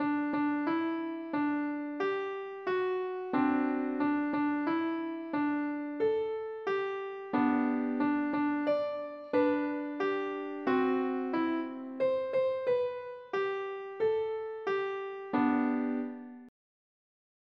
Good-Morning-to-You-piano-1x-PlayScore-version.mp3